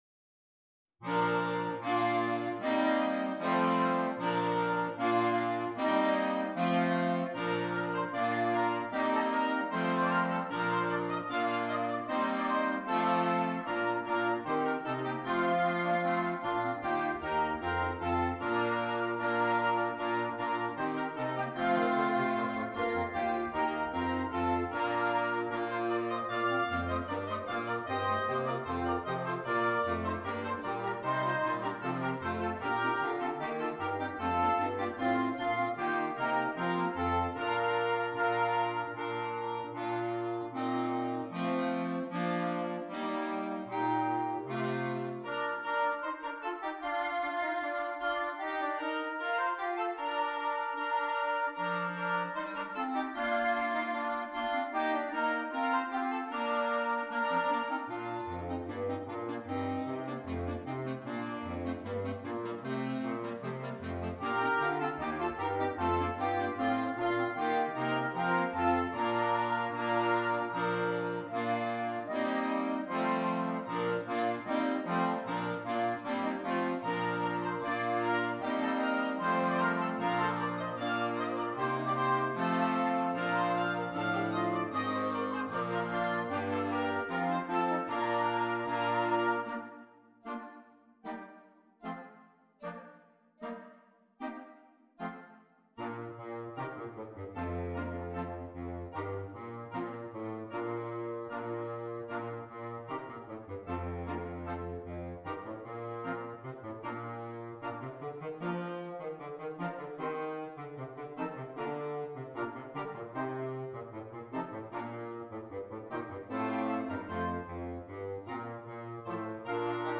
Interchangeable Woodwind Ensemble
Traditional Carol